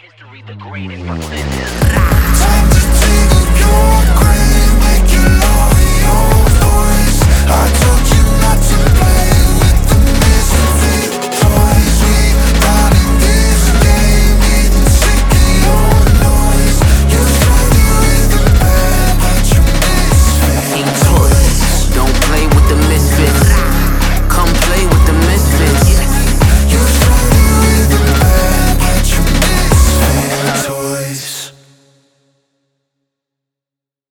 громкие
мощные
саундтреки
Trap